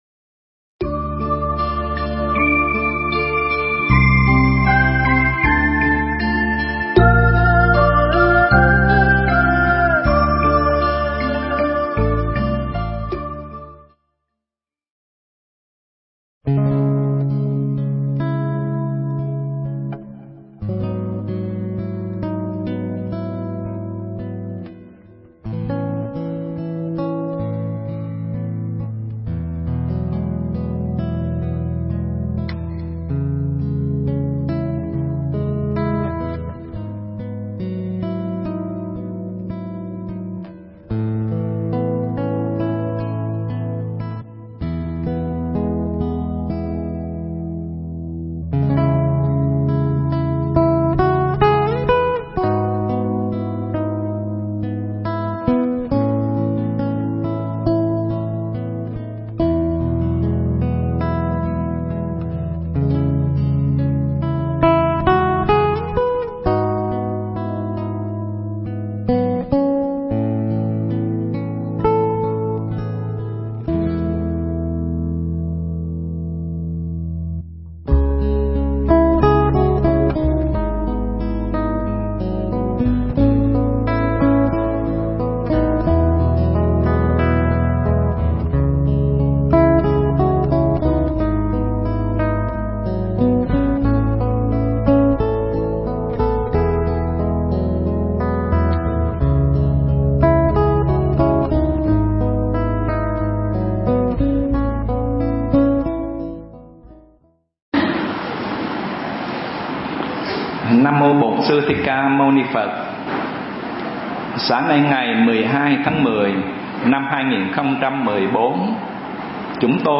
Nghe Mp3 thuyết pháp Ý Niệm Và Thực Tại Trong Thiền Phật Giáo